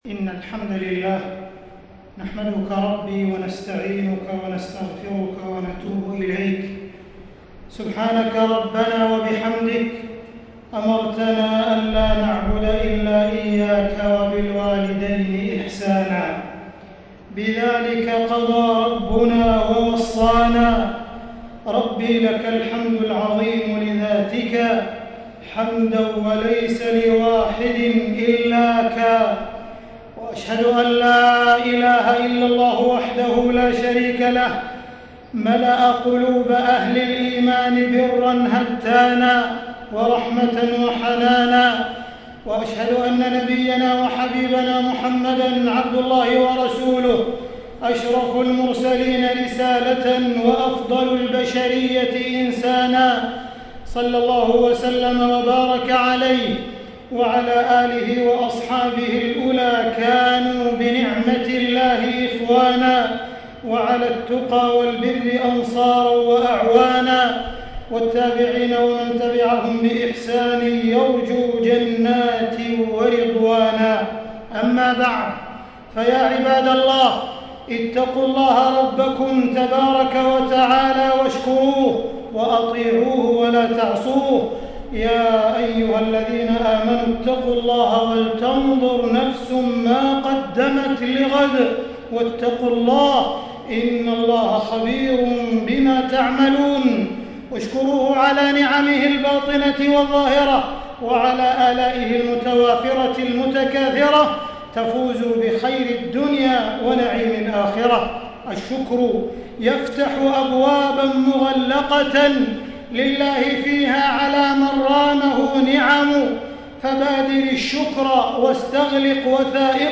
تاريخ النشر ٣٠ ربيع الأول ١٤٣٥ هـ المكان: المسجد الحرام الشيخ: معالي الشيخ أ.د. عبدالرحمن بن عبدالعزيز السديس معالي الشيخ أ.د. عبدالرحمن بن عبدالعزيز السديس وجوب بر الوالدين